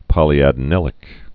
(pŏlē-ădn-ĭlĭk)